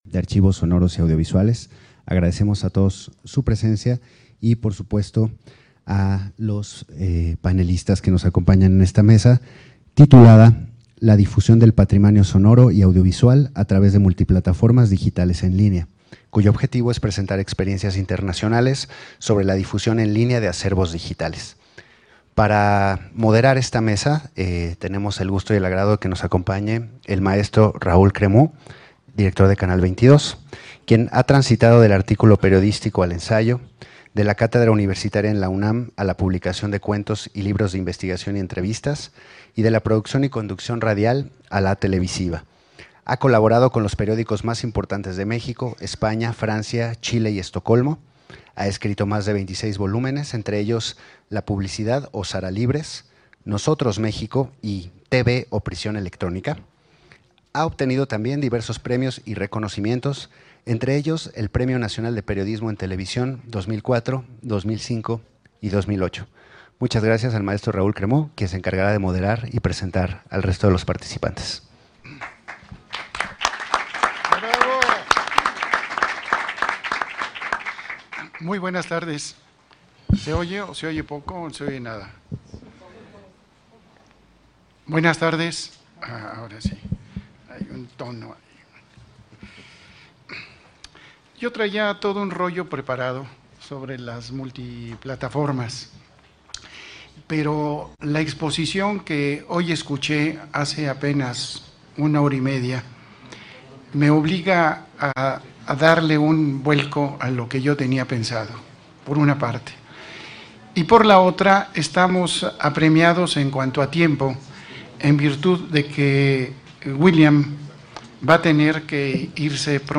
Lugar: Fonoteca Nacional de Mexico.